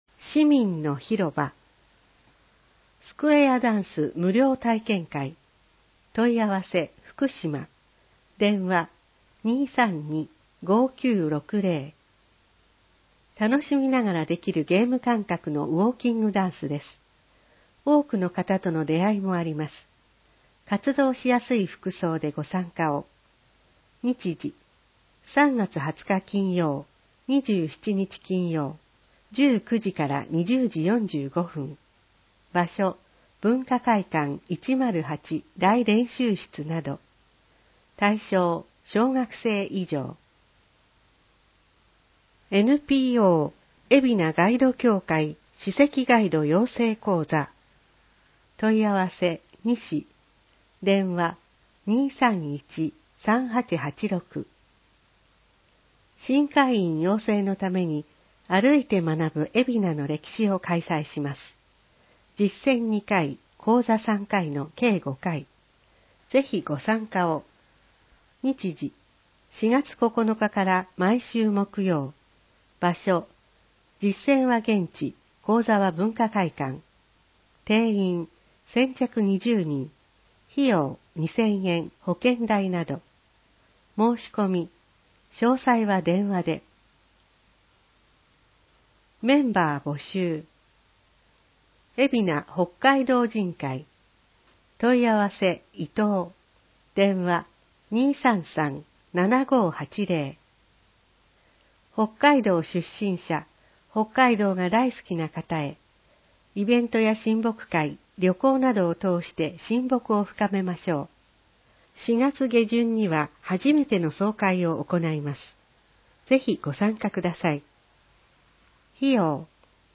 広報えびな 平成27年3月15日号（電子ブック） （外部リンク） PDF・音声版 ※音声版は、音声訳ボランティア「矢ぐるまの会」の協力により、同会が視覚障がい者の方のために作成したものを登載しています。